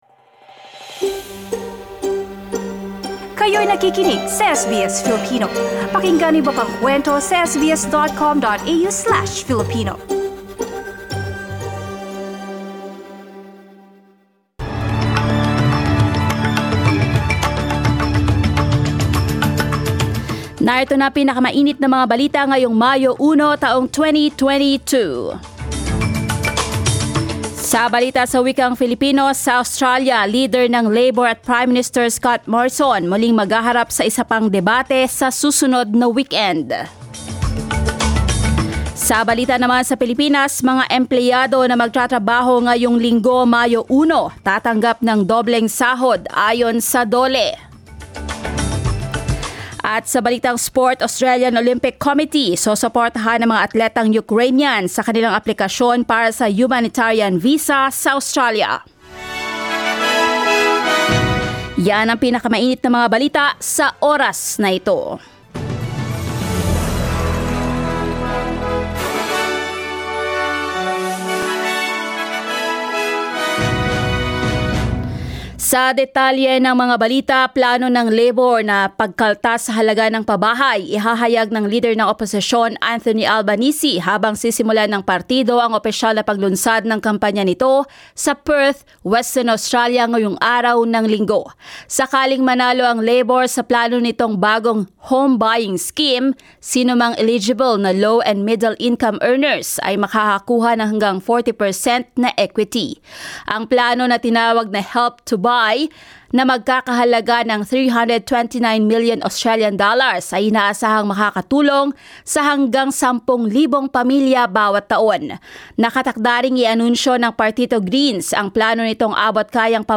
SBS News in Filipino, Sunday 1 May